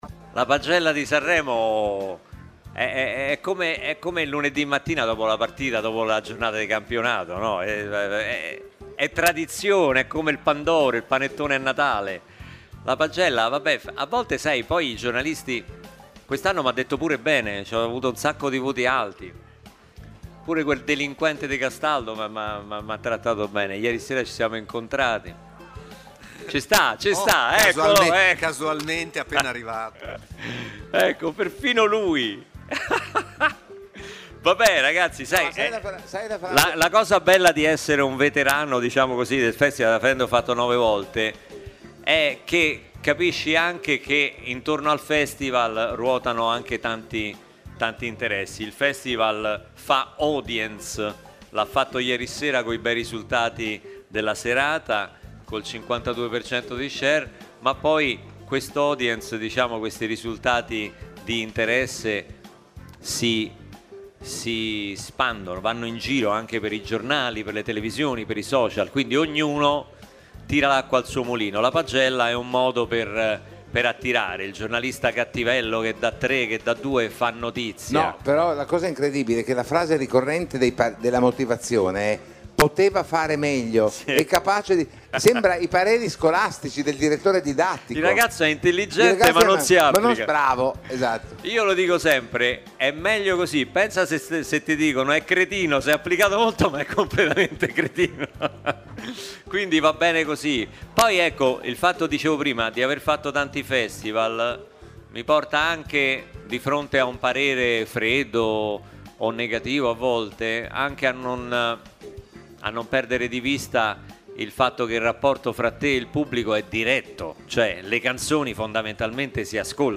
In sala stampa arriva Luca Barbarossa.